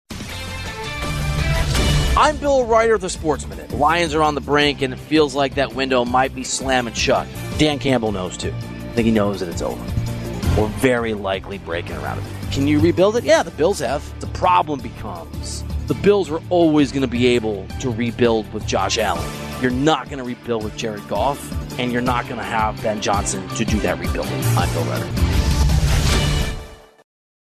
Hourly Commentaries between 6am-7pm by Infinity Sports Network talent